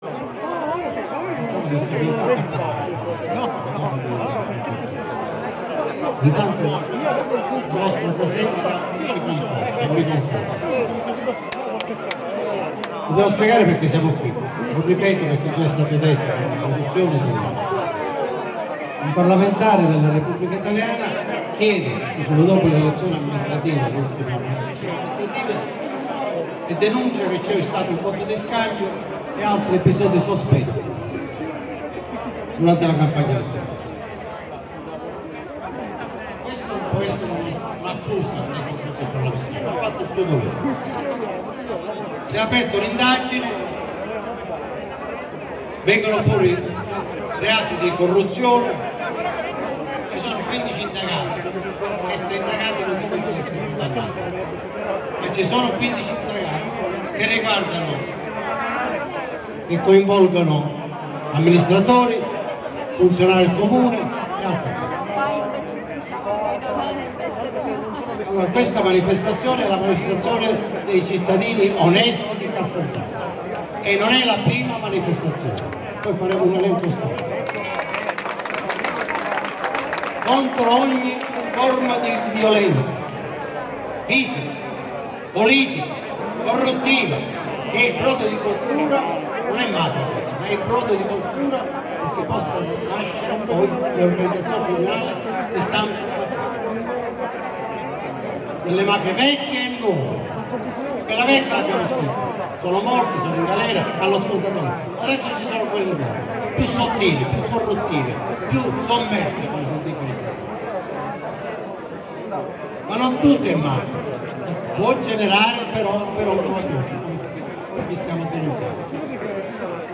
Intervento
Sit-in contro la malapolitica e il malaffare a Casteldaccia (Pa)